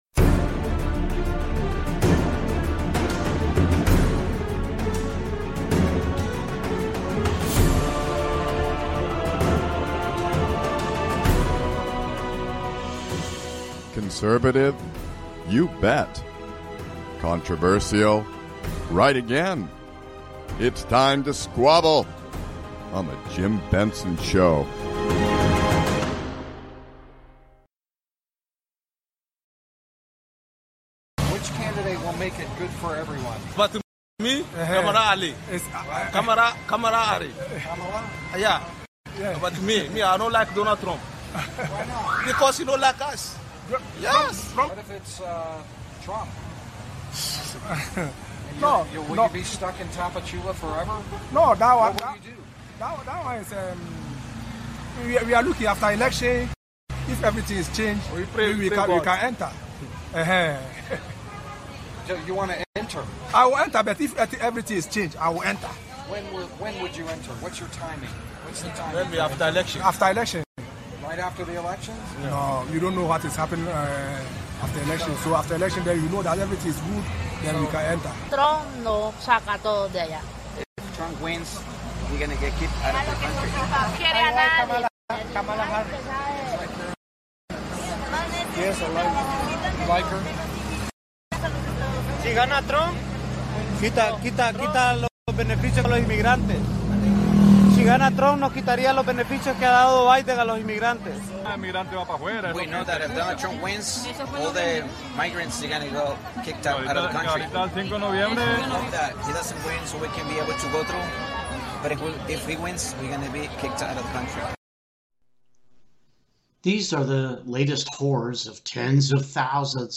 conservative talk radio done right, addressing the issues that concern you.